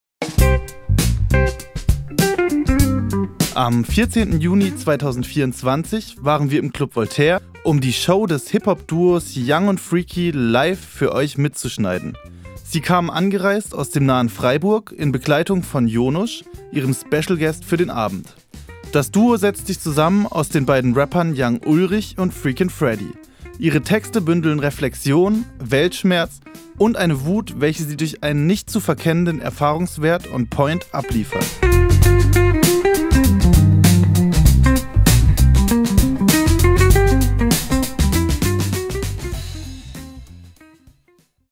Hiphop
Rappern